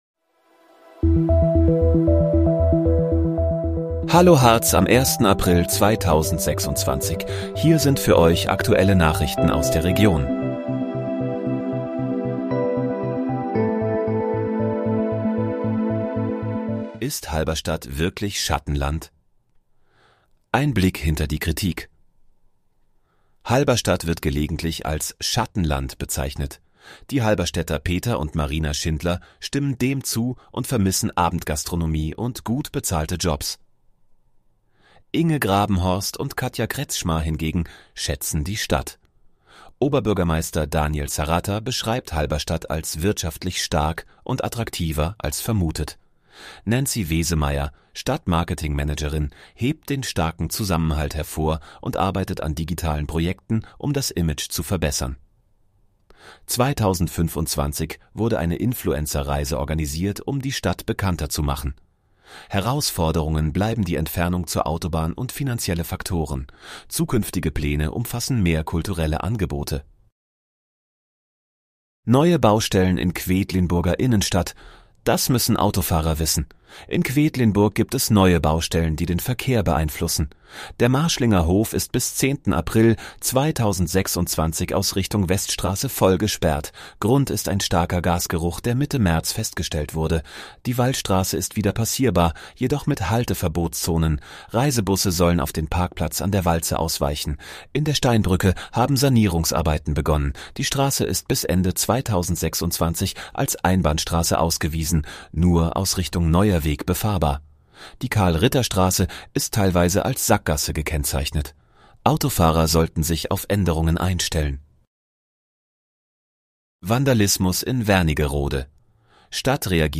Hallo, Harz: Aktuelle Nachrichten vom 01.04.2026, erstellt mit KI-Unterstützung